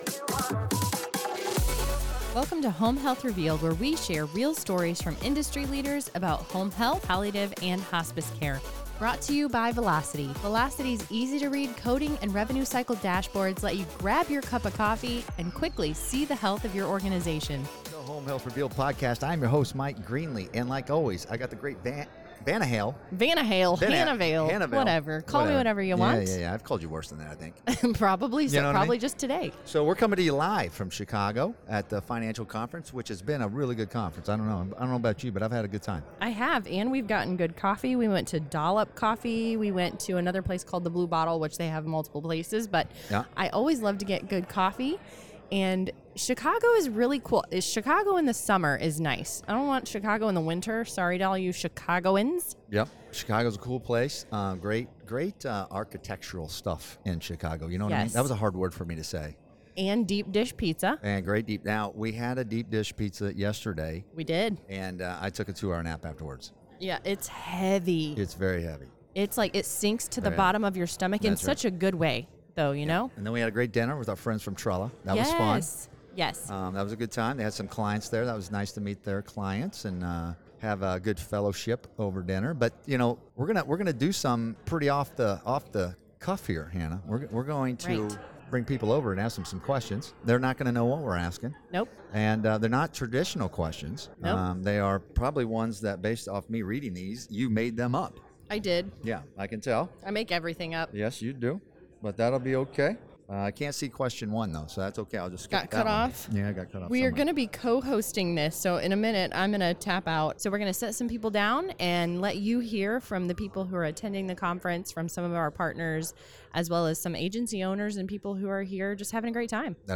Home Health Revealed went on the road! During the Alliance conference, we grabbed the mics, hit “record,” and brought the conversations straight to you—uncut, unscripted, and right from the heart of the action. From leadership lessons to real-world strategies, you’ll hear the voices shaping the future of home health.